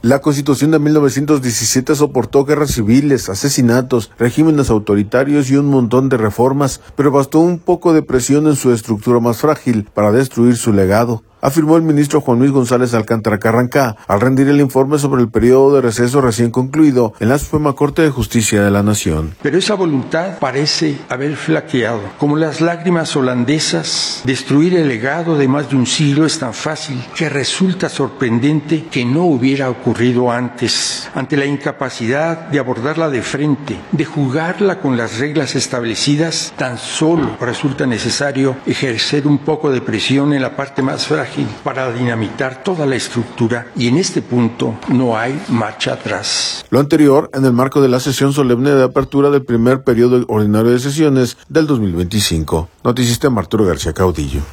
Lo anterior, en el marco de la sesión solemne de apertura del primer periodo ordinario de sesiones del 2025.